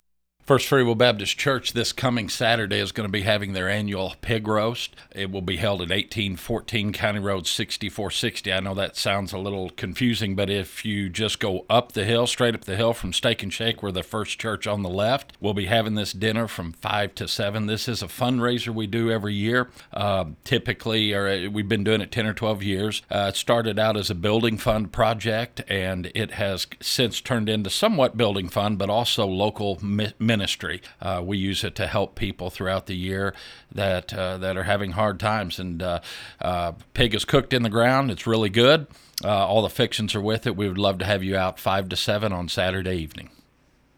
1st-Freewill-Pig-Roast.wav